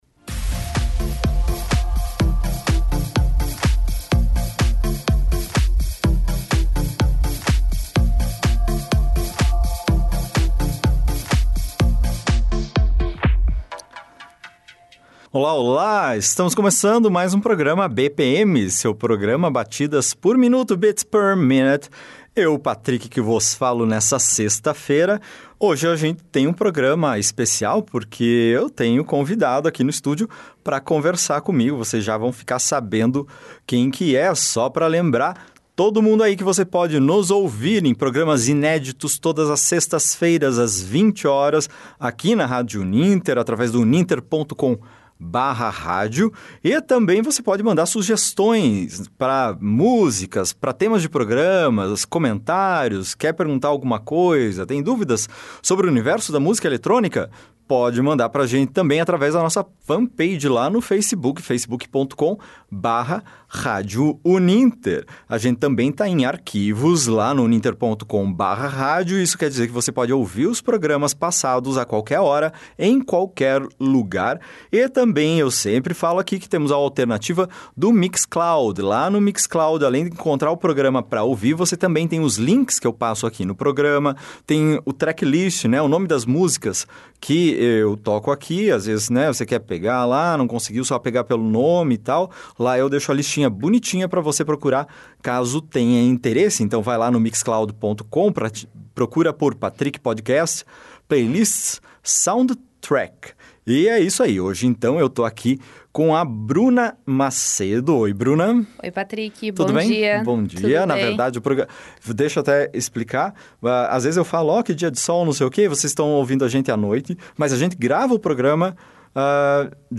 você confere uma entrevista com a publicitária e produtora de eventos